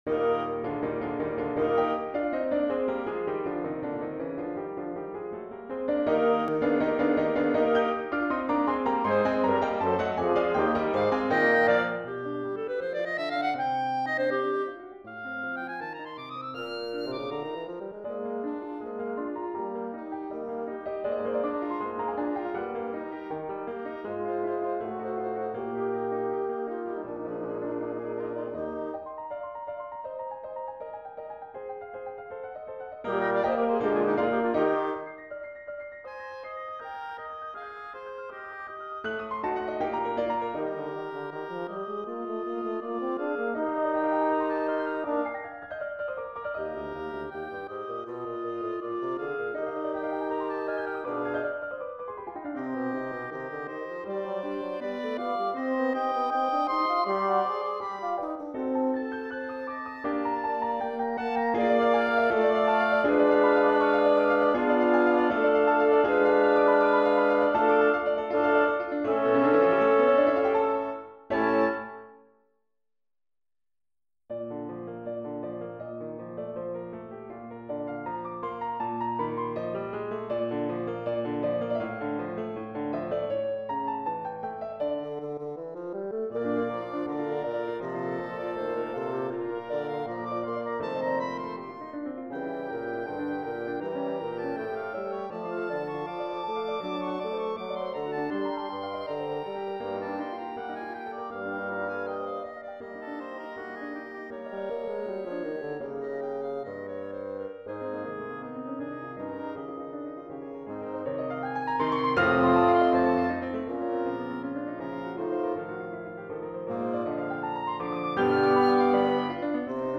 We apologize for the quality
have had to resort to midi files
For Flute, Clarinet, Horn, Bassoon and Piano